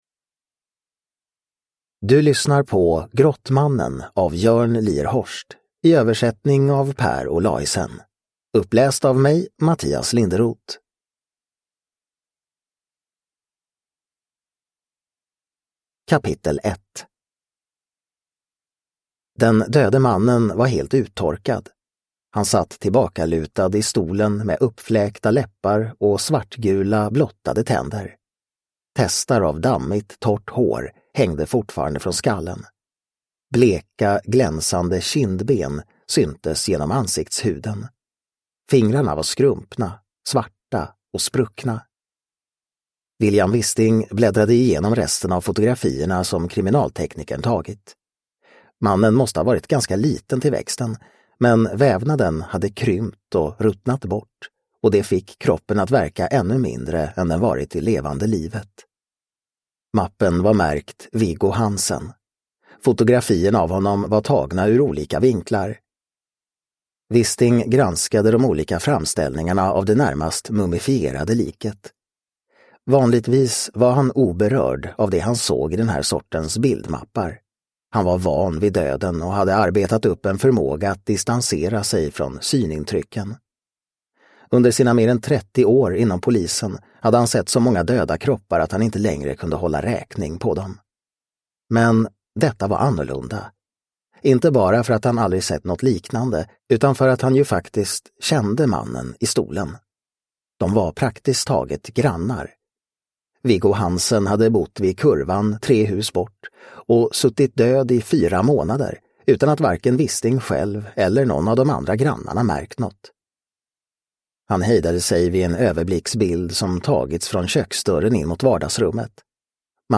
Grottmannen – Ljudbok – Laddas ner